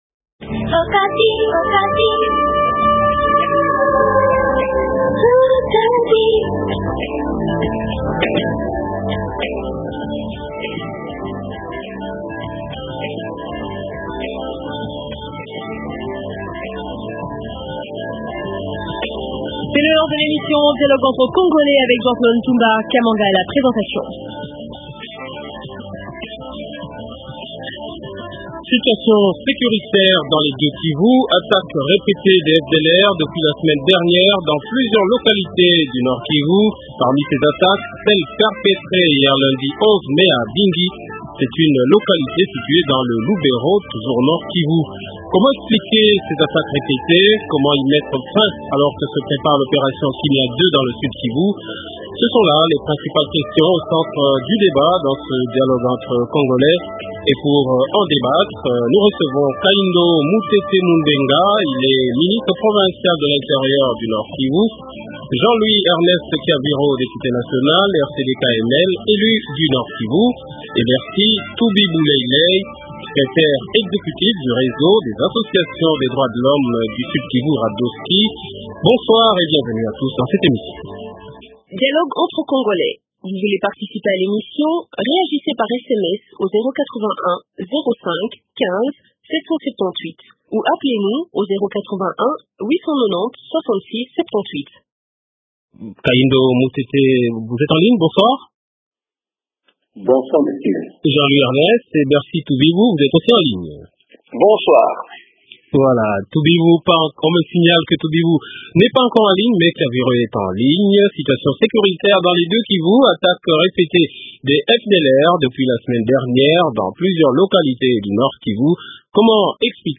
Jean Louis Ernest Kyaviro, député national Rcd/Kml, élu du Nord KiVU